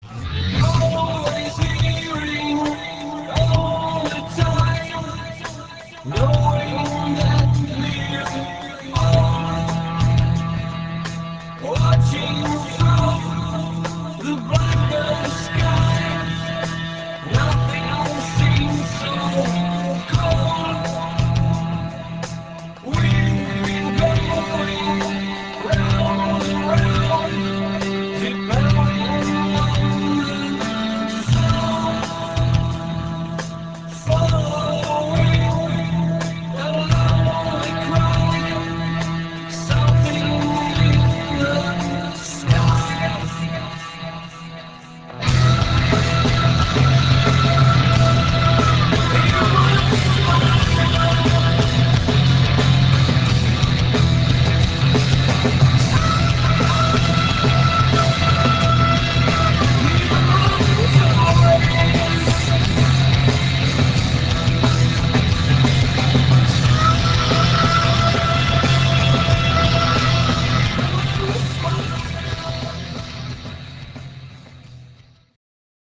152 kB MONO